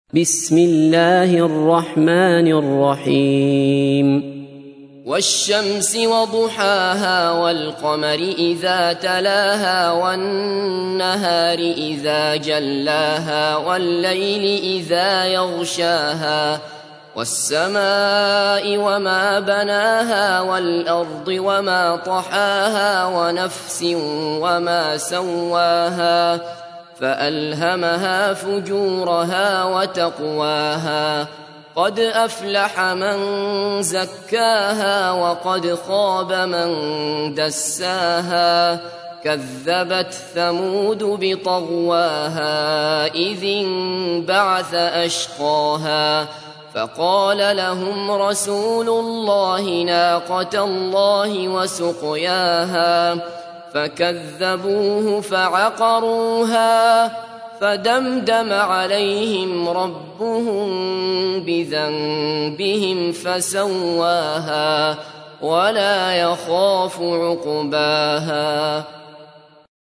تحميل : 91. سورة الشمس / القارئ عبد الله بصفر / القرآن الكريم / موقع يا حسين